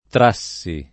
tr#SSi]; imperf. cong. traessi [tra%SSi]; imper. trai [tr#i]; ger. traendo [tra$ndo]; part. pres. traente [tra$nte]; part. pass. tratto [tr#tto] — per trai, trae, poet. traggi [tr#JJi], tragge [